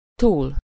Ääntäminen
Ääntäminen Tuntematon aksentti: IPA: /tə/ Haettu sana löytyi näillä lähdekielillä: hollanti Käännös Ääninäyte 1. túl 2.